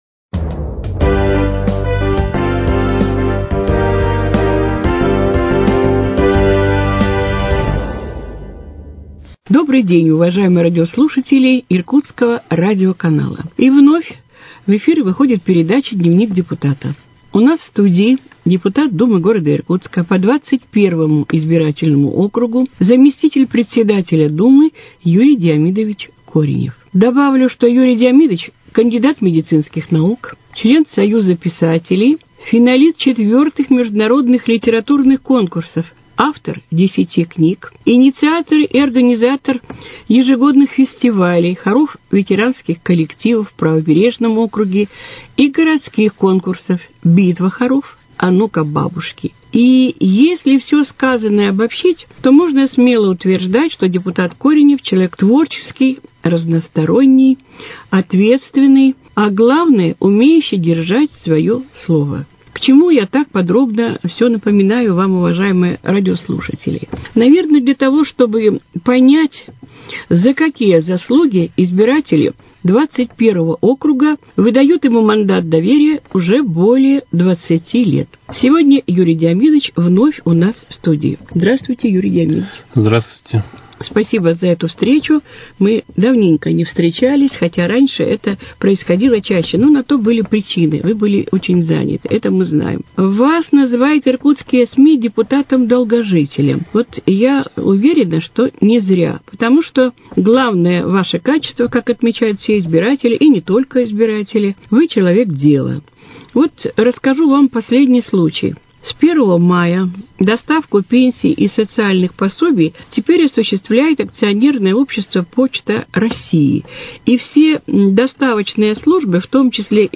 Вашему вниманию выпуск передачи с участием депутата Думы г.Иркутска по 21 избирательному округу Юрия Коренева.